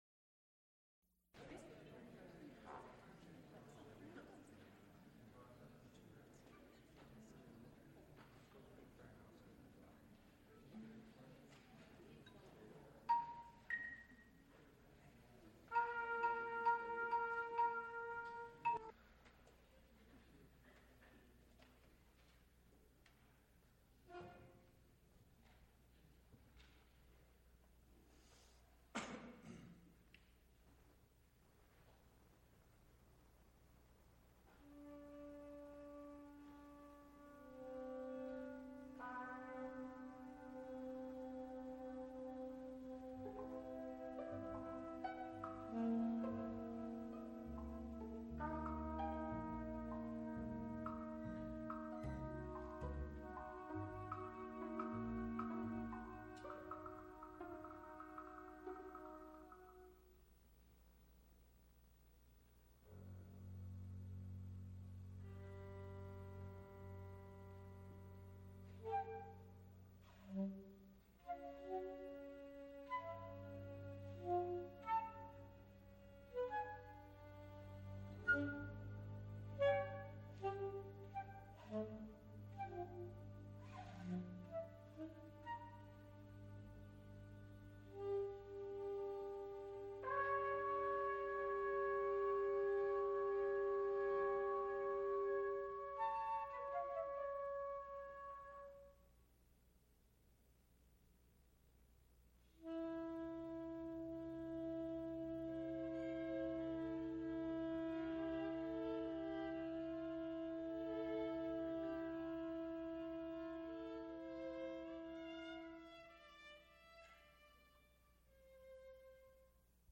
String trio
Recorded live March 6, 1975, Frick Fine Arts Auditoruium, University of Pittsburgh.
Extent 2 audiotape reels : analog, quarter track, 7 1/2 ips ; 7 in.
Music--20th century String trios